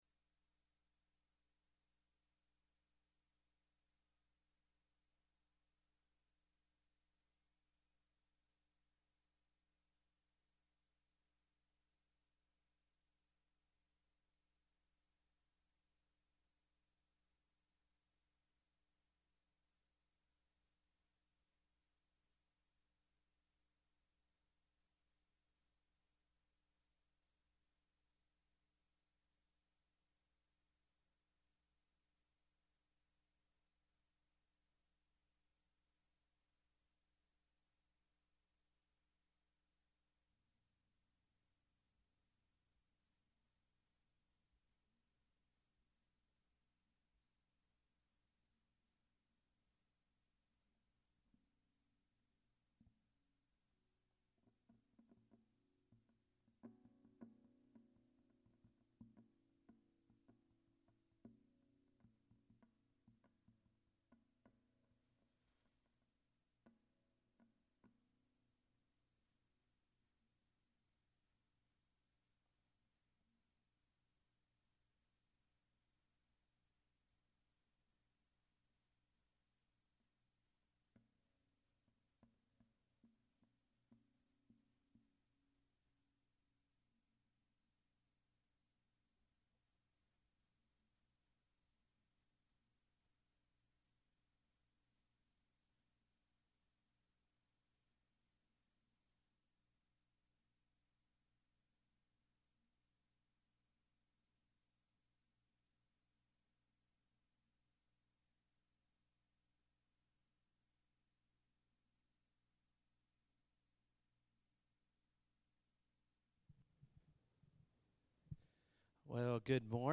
Sunday Sermon September 24, 2023